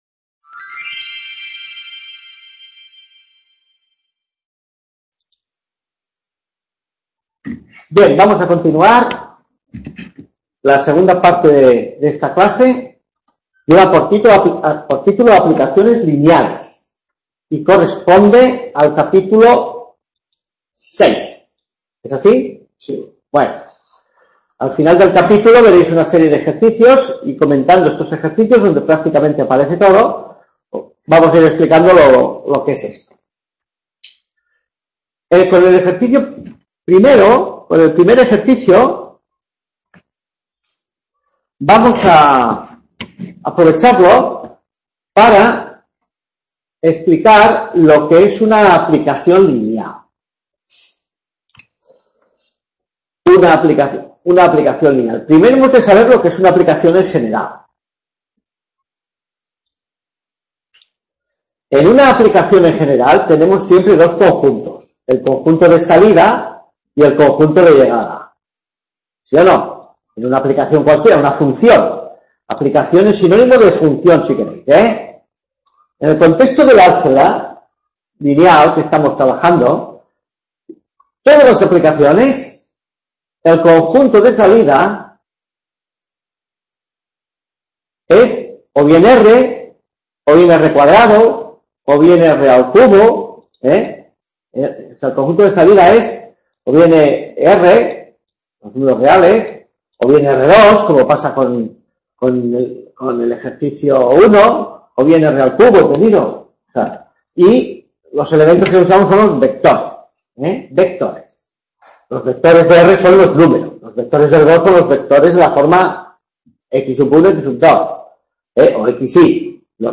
Clase 3 mates 2 q2 1415 Aplicaciones lineales | Repositorio Digital